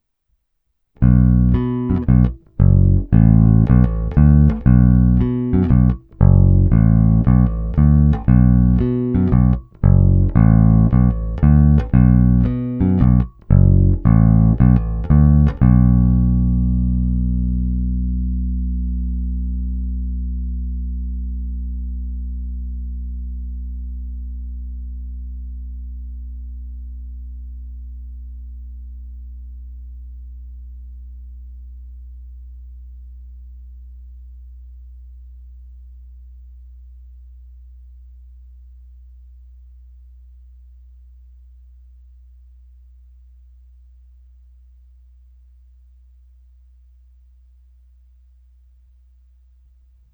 "Povinné" nahrávky – hráno nad snímačem